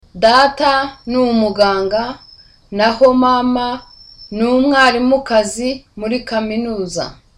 Dialogue
(Smiling)